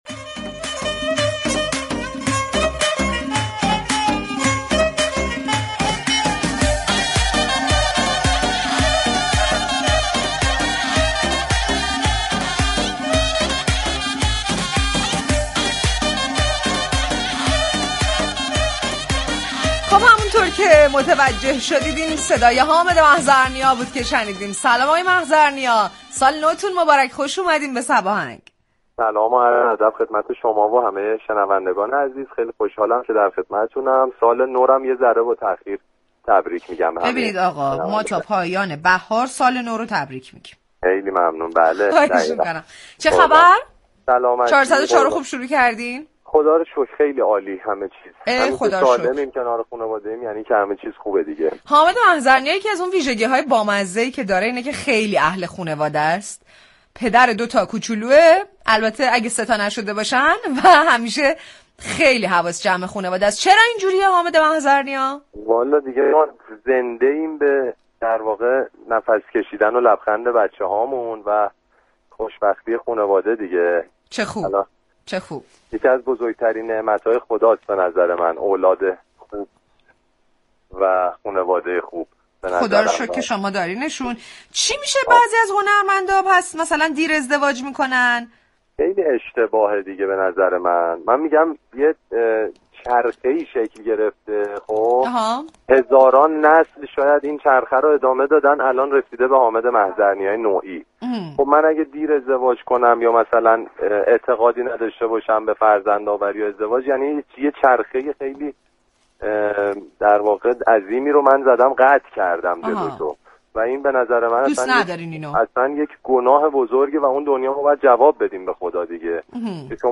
حامد محضرنیا خواننده پاپ در گفتگو با برنامه «صباهنگ» از علاقه‌اش به فرزندآوری گفت و ازدواج نكردن و بچه‌دار نشدن را ناشكری و گناه خطاب كرد.